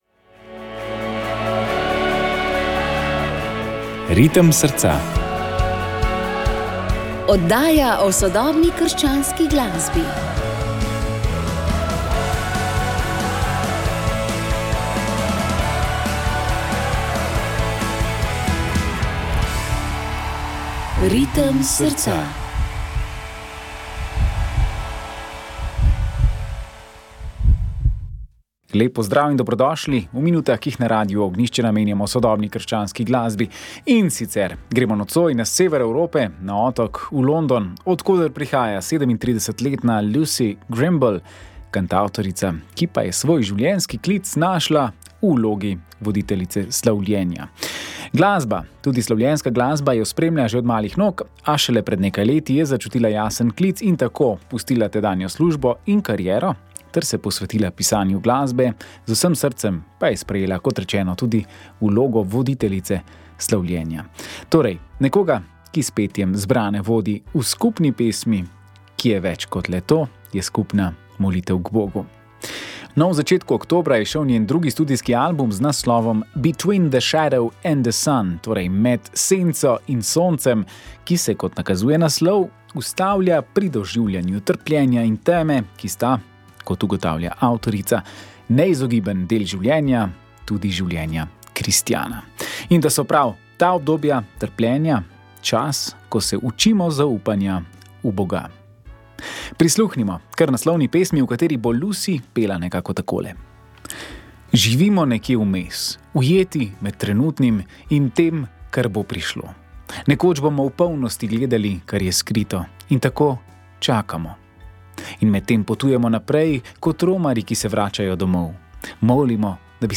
Francoska sodobna krščanska glasba
Tokrat smo oddajo posvetili francoski sodobni krščanski glasbi, torej pesmim v francoskem jeziku.